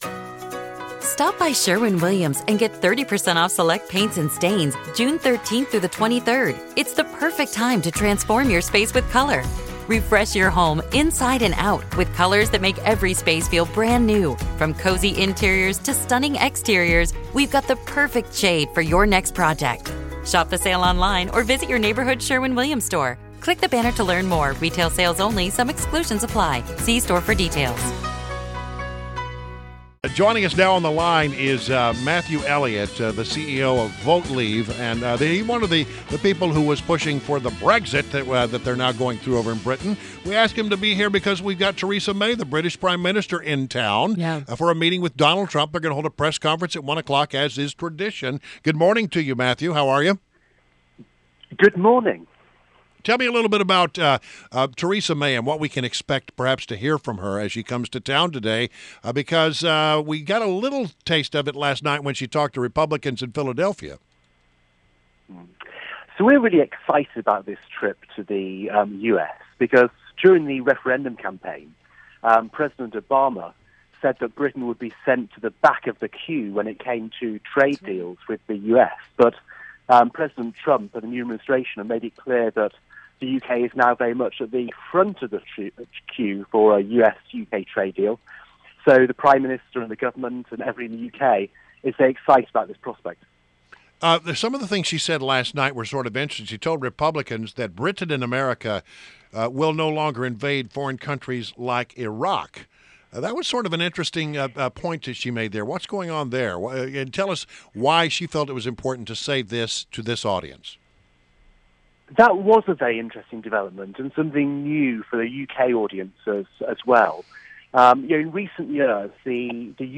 Mornings on the Mall / WMAL Interview